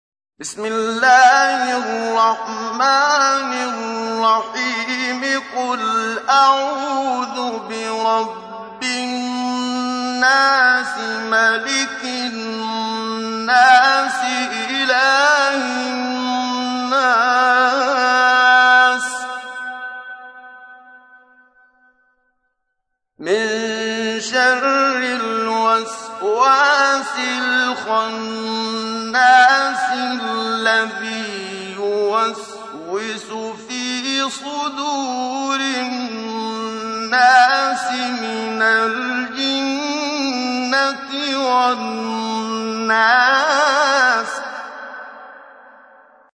تحميل : 114. سورة الناس / القارئ محمد صديق المنشاوي / القرآن الكريم / موقع يا حسين